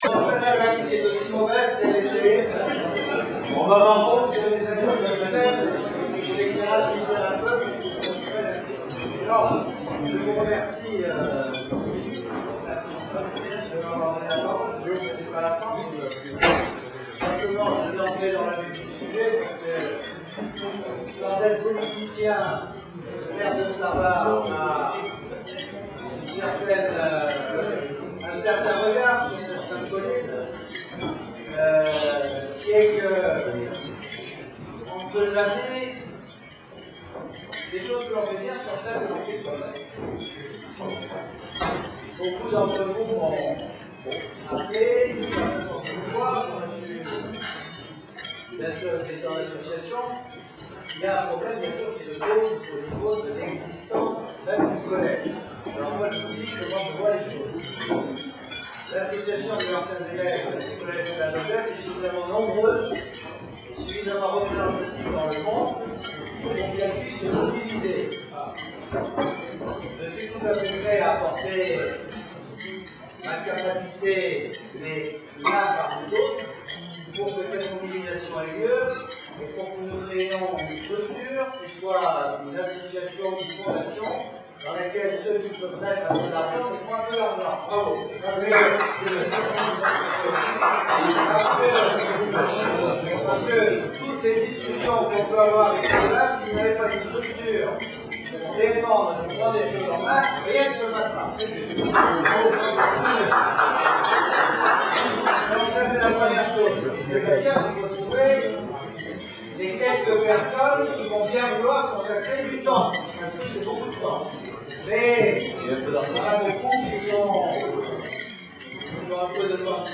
Fête des Anciens du 25 avril 2004
Discours de Jean-Jacques de Peretti (promo 63), ancien ministre, maire de Sarlat